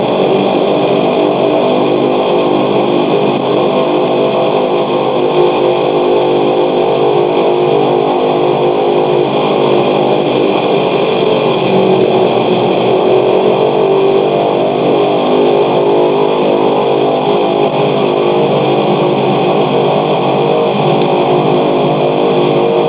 DC-3 Sound Files
Pre-flight number 1& 2 (simultaneous) engines check from idle to full throttle (recorded from inside the cockpit).